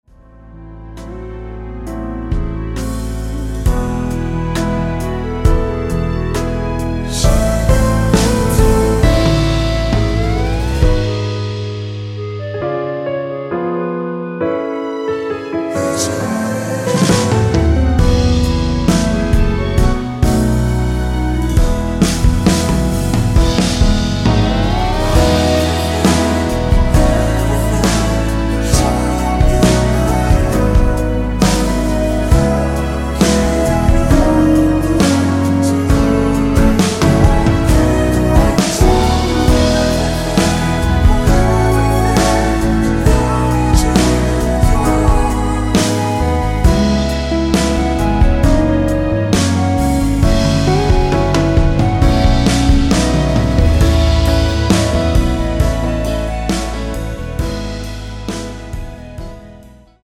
원키에서(-3)내린 멜로디와 코러스 포함된 MR입니다.(미리듣기 확인)
앞부분30초, 뒷부분30초씩 편집해서 올려 드리고 있습니다.
중간에 음이 끈어지고 다시 나오는 이유는